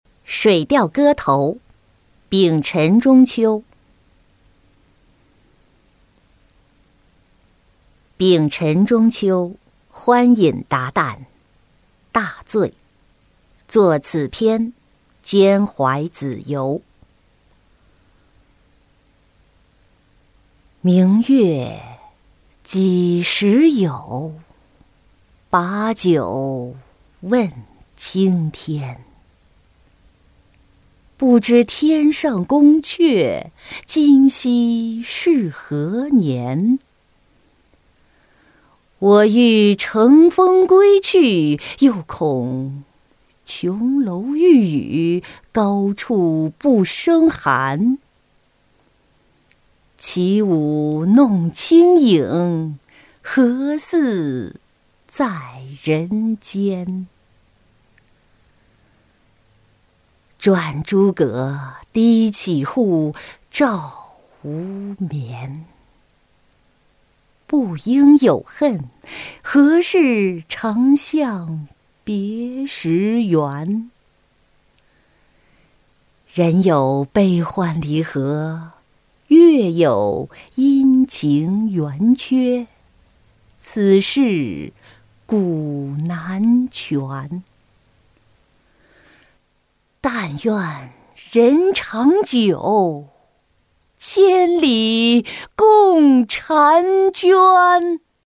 水调歌头·明月几时有-音频朗读